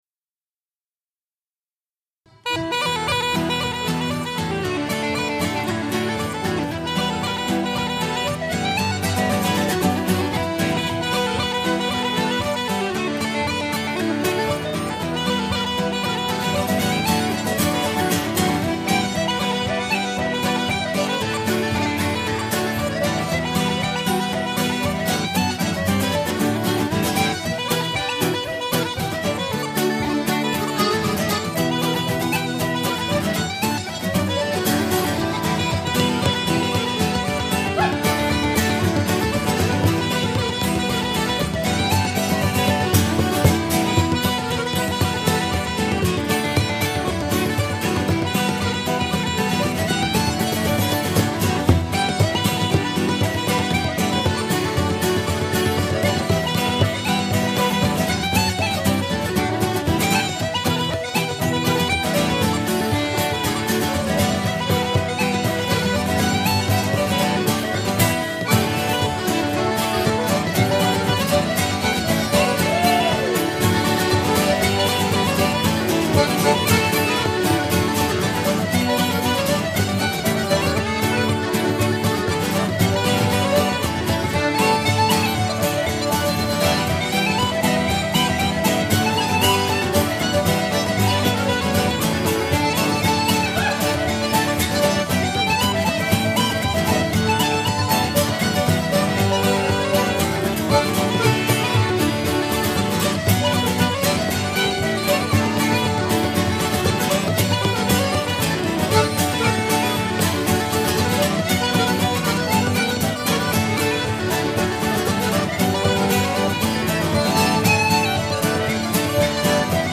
LIVE AT THE BLACKSTONE RIVER THEATER
(No mix here - recorded straight off the board.)
reels